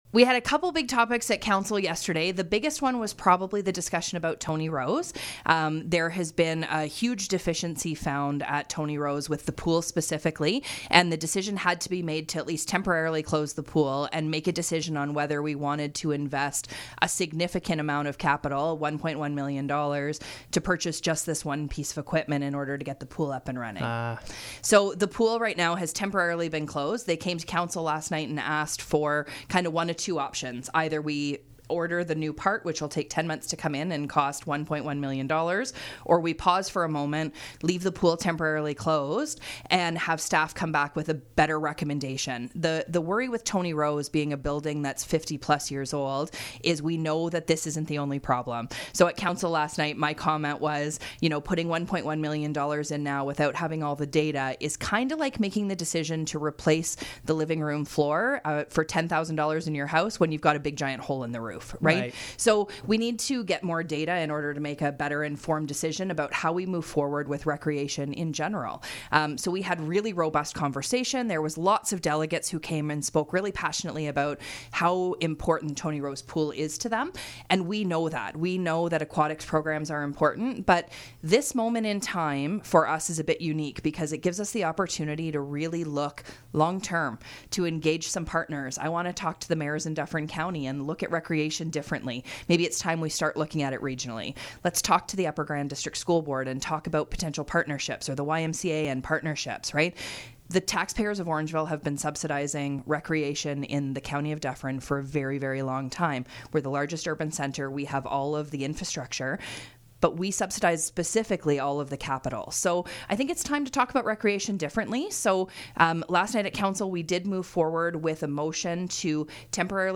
I had a chat with Mayor Lisa Post about the future of the Tony Rose Memorial Sports Centre.